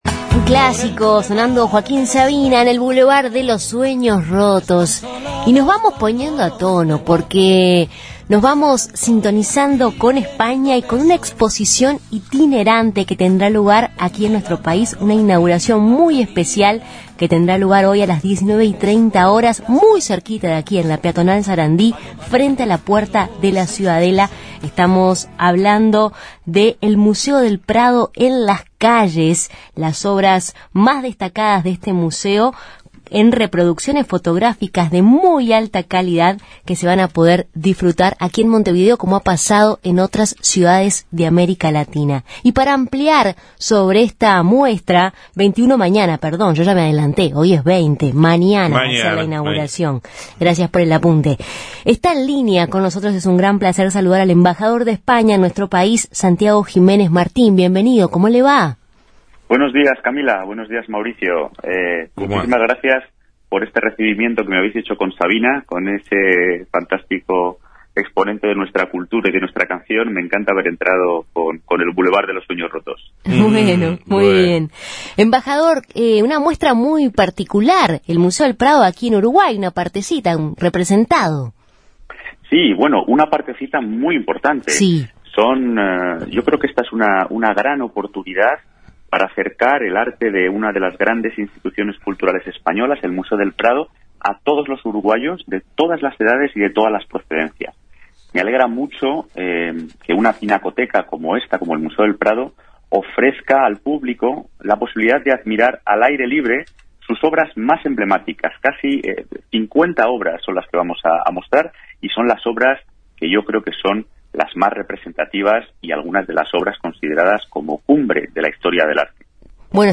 “Esta es una gran oportunidad para acercar el arte de una de las grandes instituciones españolas como es el Museo del Prado, a todos los uruguayos, de todas las edades y de todas las procedencias”, dijo en Justos y pecadores el embajador de España en Uruguay, Santiago Jiménez Martín.